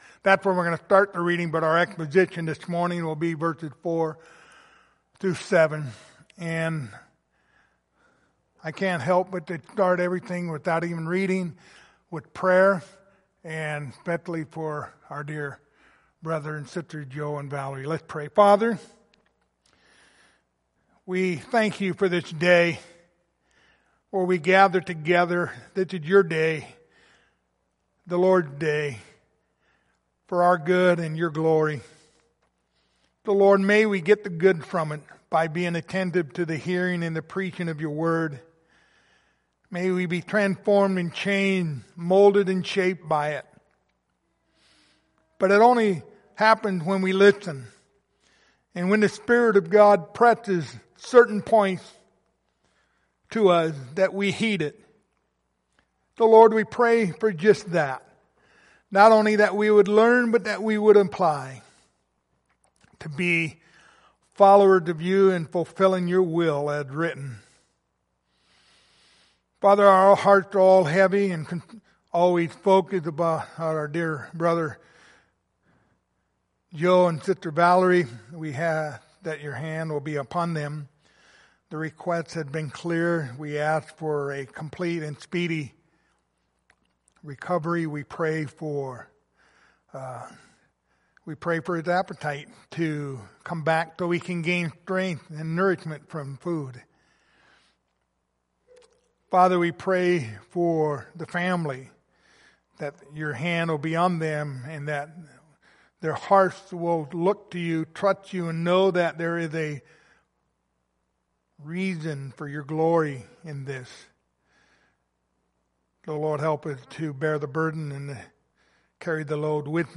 Passage: Titus 3:4-7 Service Type: Sunday Morning